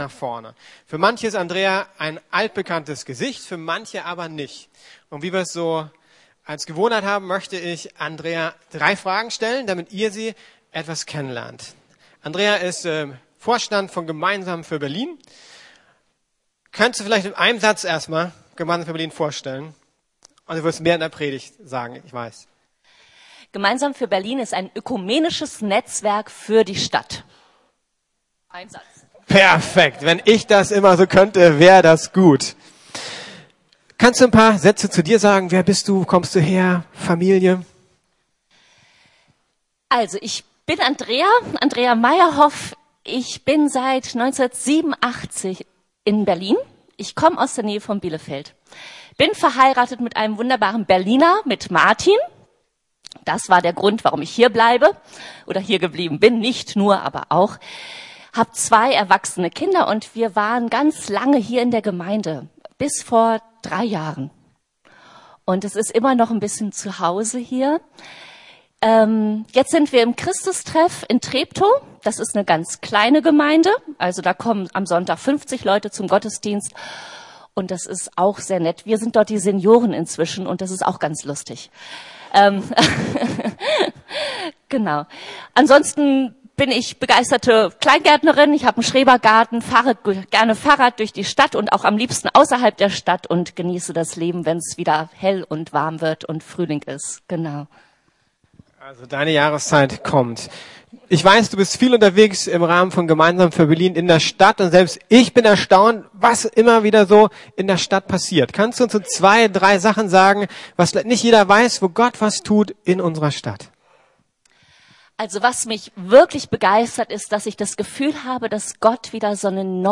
EINS sein kann man nicht allein ~ Predigten der LUKAS GEMEINDE Podcast